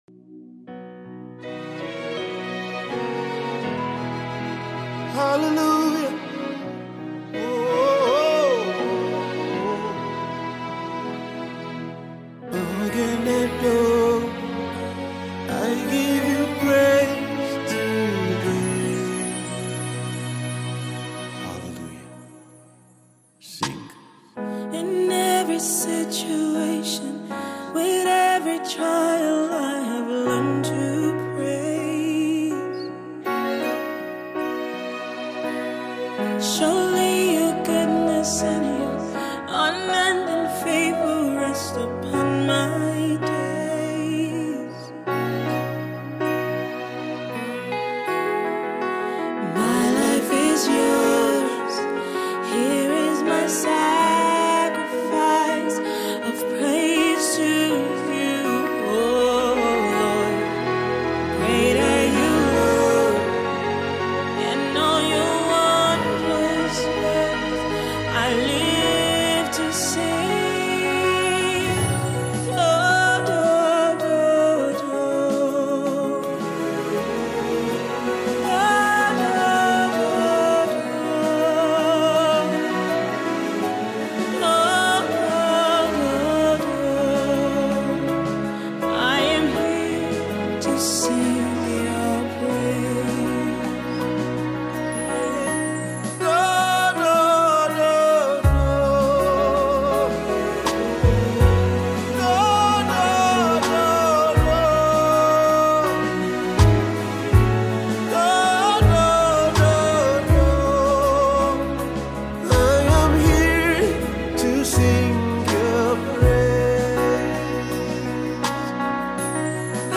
worship song
On this spirit-filled song